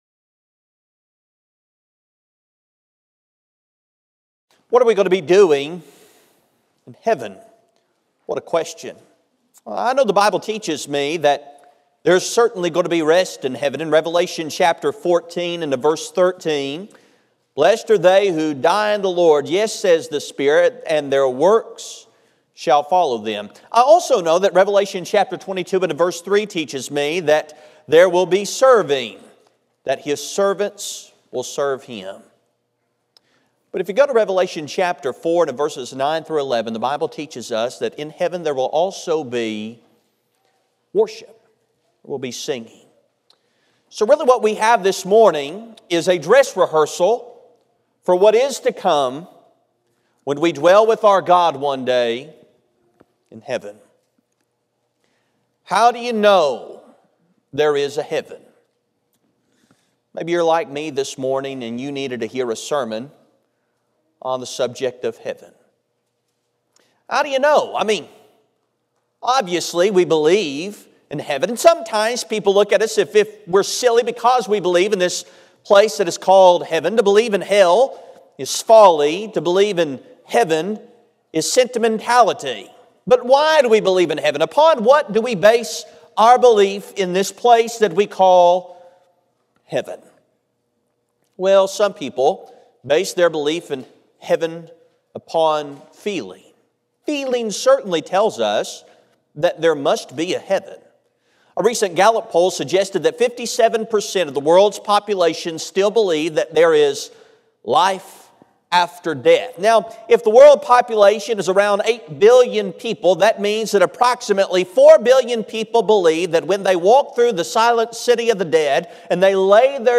The sermon is from our live stream on 10/27/2024